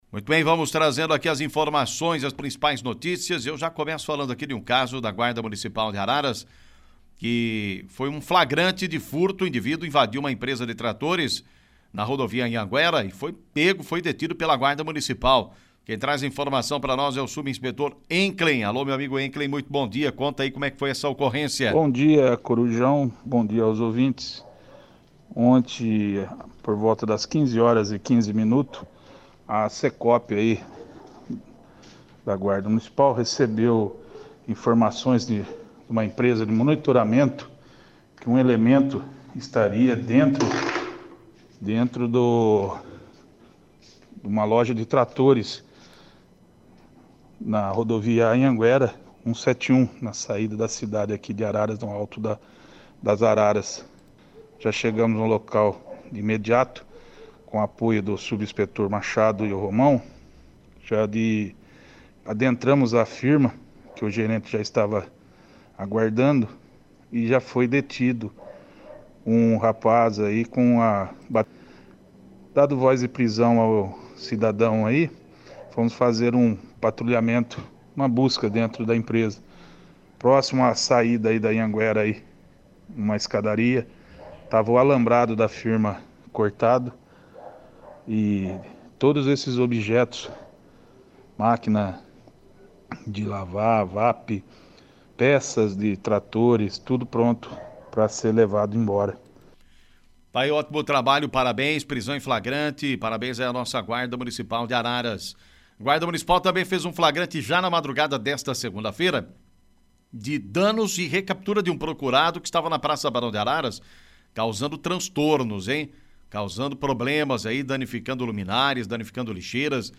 Clique no link abaixo e ouça as principais notícias ocorridas em Araras e região na voz do repórter policial